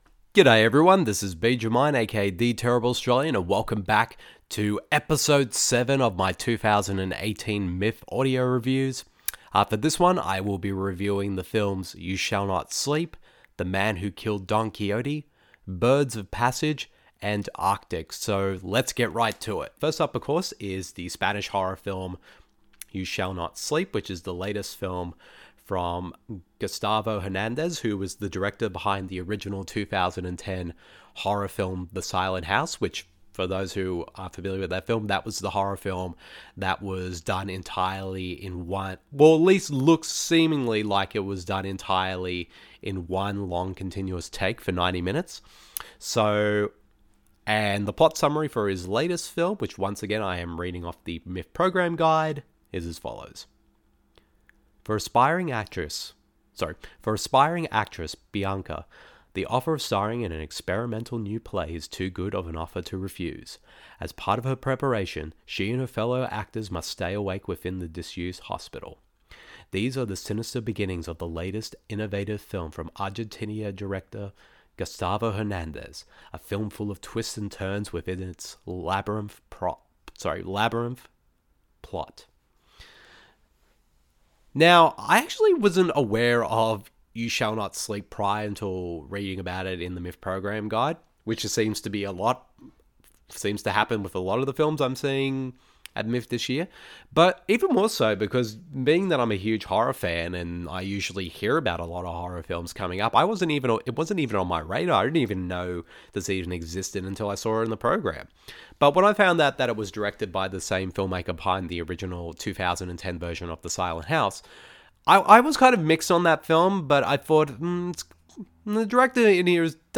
Welcome to the 7th edition of my 2018 Melbourne International Film Festival (a.k.a. MIFF) audio reviews. In this one, I share my thoughts on the Spanish supernatural horror film YOU SHALL NOT SLEEP, director Terry Gilliam’s 25 year long in-development passion project THE MAN WHO KILLED DON QUIXOTE, the Colombian crime saga BIRDS OF PASSAGE and Mad Mikkelsen starring survival drama ARCTIC.